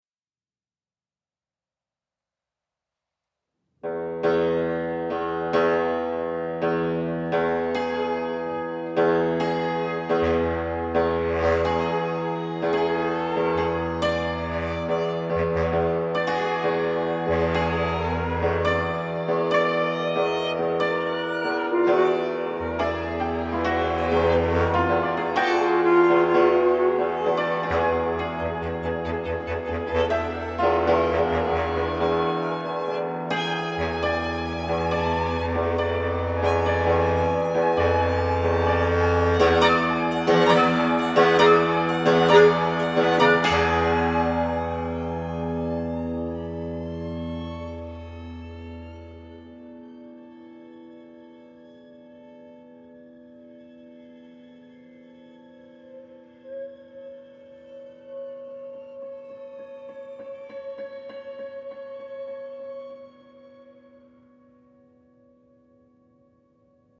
adding violin, flute, and bass clarinet to the cimbalom.